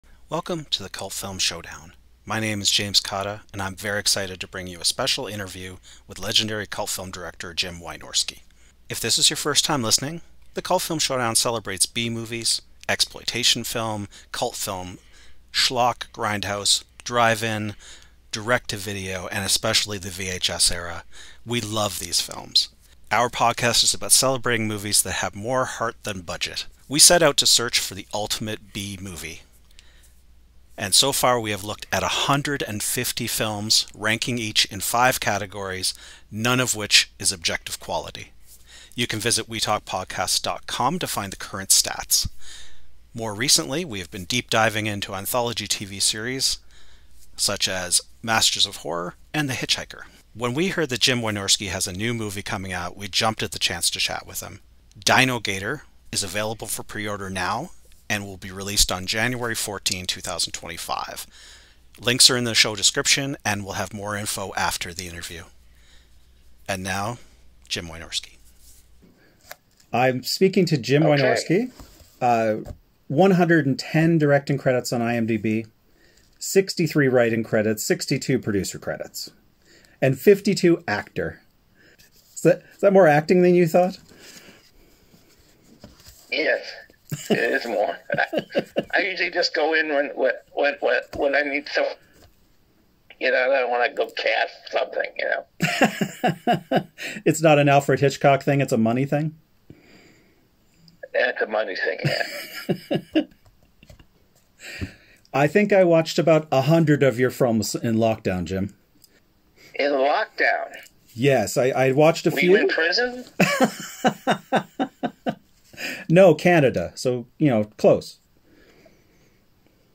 CFS Presents - Dinogator! Interview with director Jim Wynorski about his latest monster movie!
CFS_Dinogator_Interview_With_Director_JimWynorski.mp3